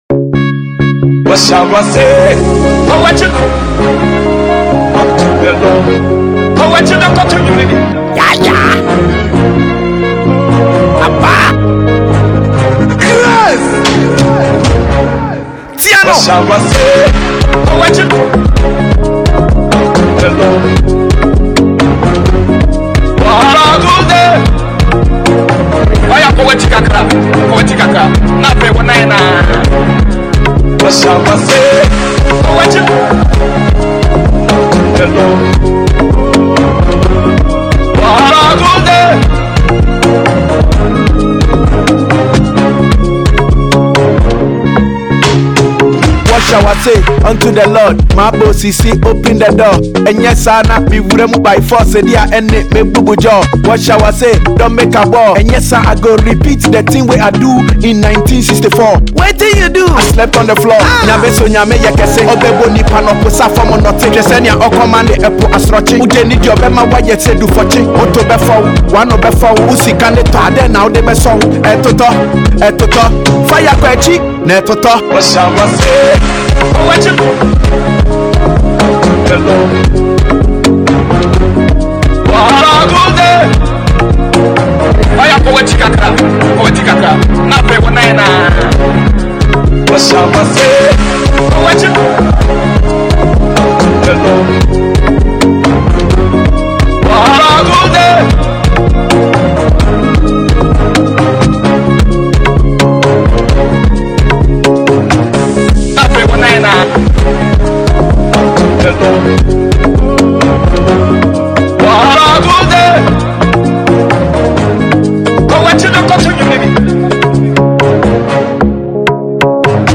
Ghanaian rapper and songwriter